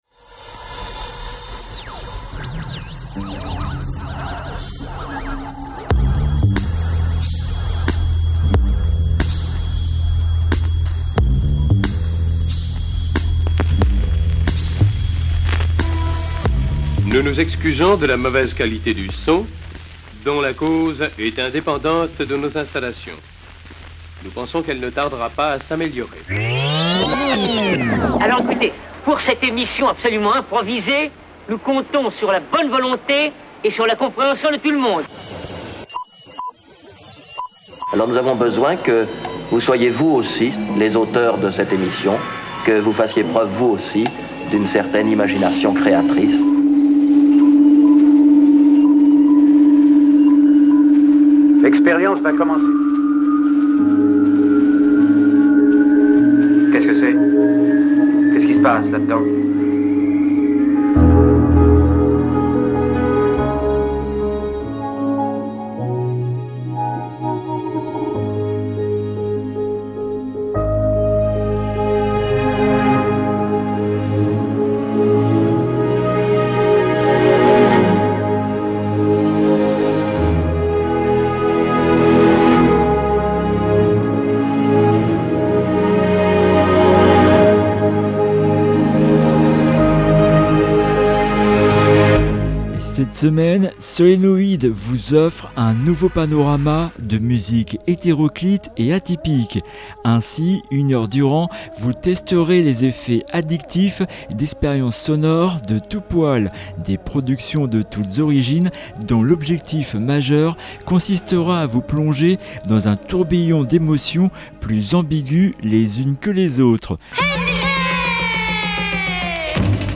Cette semaine, Solénoïde vous offre un nouveau panorama de musiques hétéroclites et atypiques, avec pas moins de 10 projets ayant la particularité d’incarner 10 nationalités différentes. Cette émission, en forme de grand huit musical, vous conduira de la Suisse à la Nouvelle Orléans, de la Turquie à Berlin, du Nigéria à Turin, en passant par le Kazakhstan, Moscou, Nottingham et la Norvège !